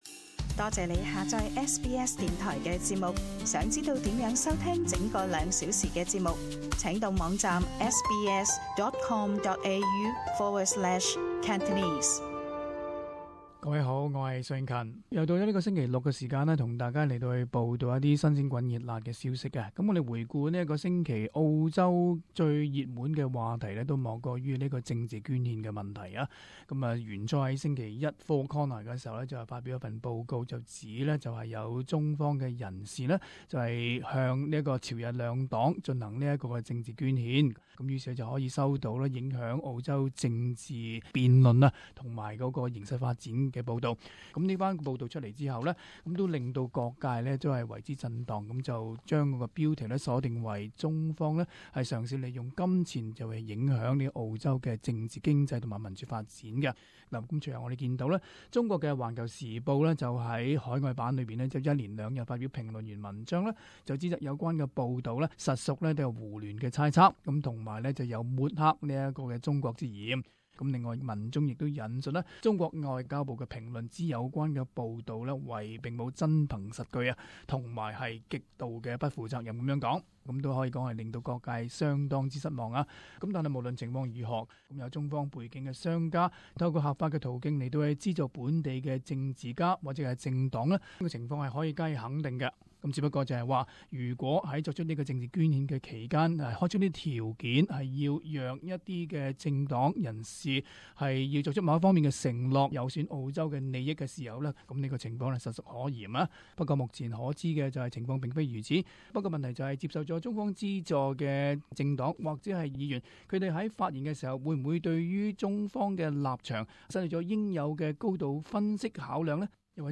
【時事報導】探討澳洲政治捐獻的漏洞